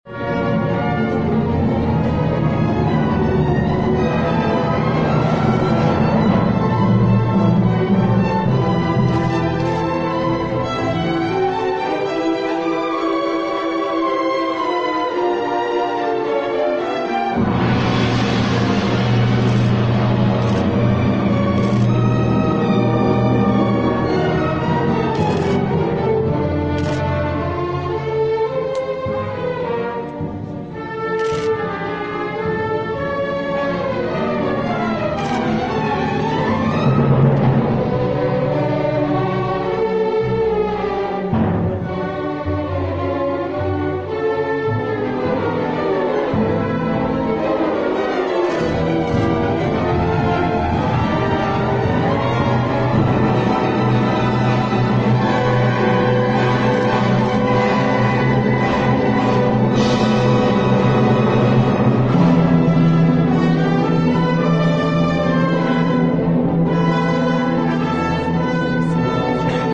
سمفونی «روح الله» به آهنگسازی شاهین فرهت شب گذشته با حضور برخی چهره های سیاسی ، فرهنگی و هنری در تالار وحدت رونمایی و با اركستر سمفونیك تهران اجرا شد.